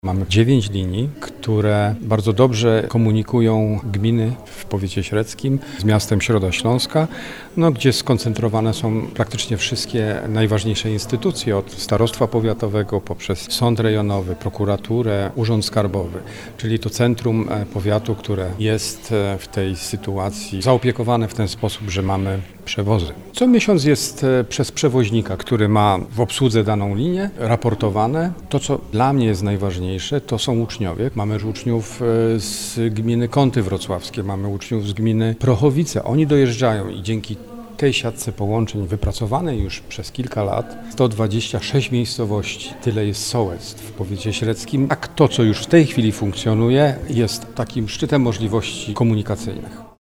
Starosta Średzki Sebastian Burdzy wyjaśnia, jak obecnie wygląda komunikacja autobusowa w powiecie i co można jeszcze poprawić.